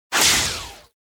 powerup.mp3